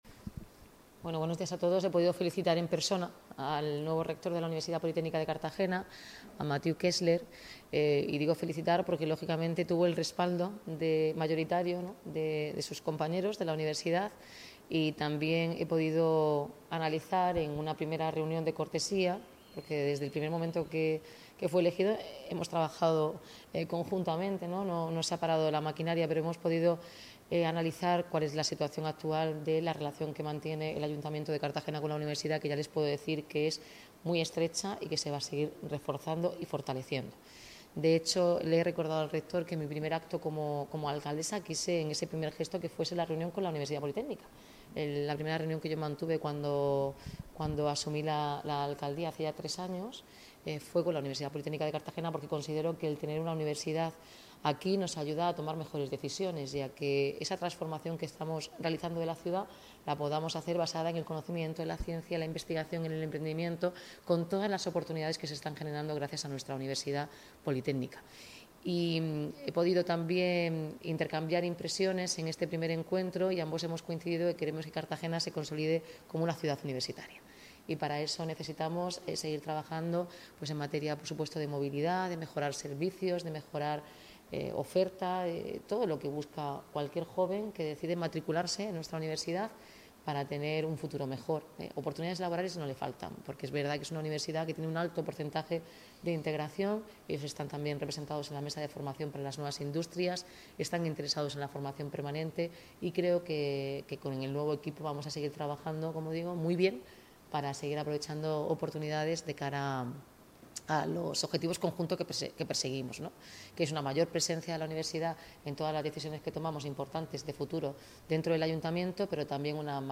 Enlace a Declaraciones de Noelia Arroyo y Mathieu Kessler